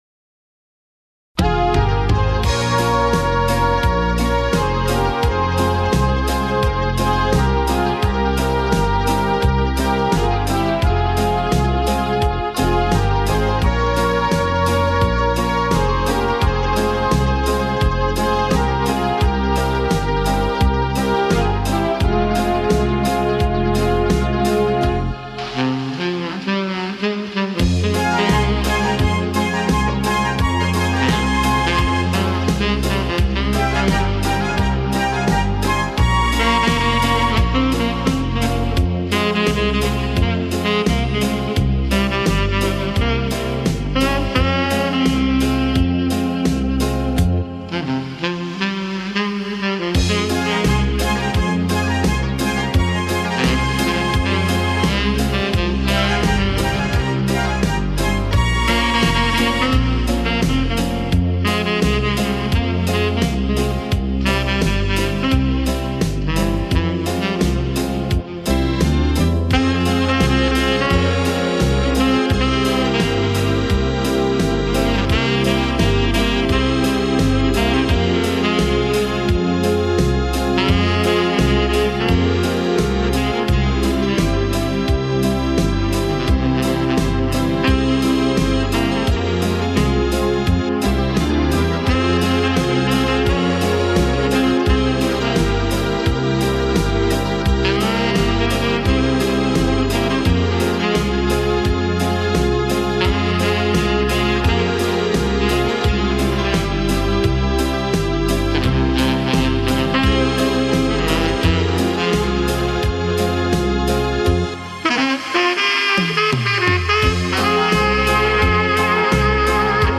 саксофон